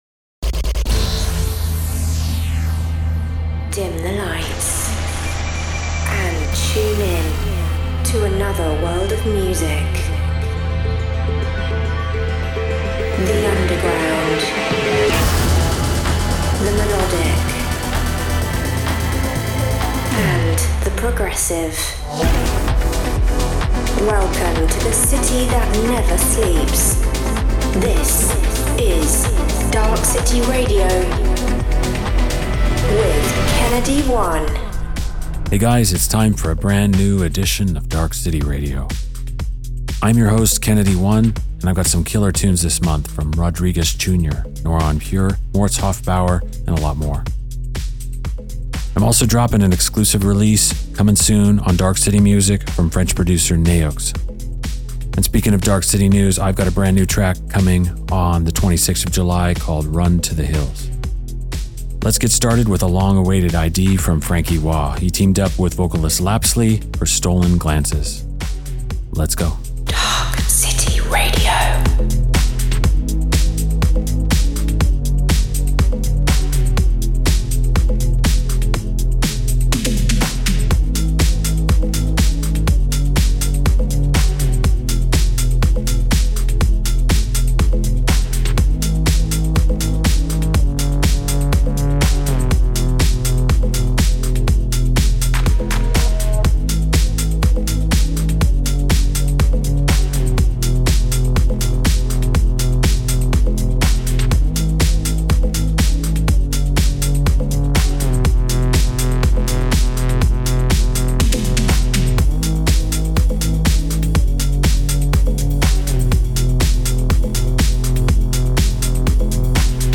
underground melodic techno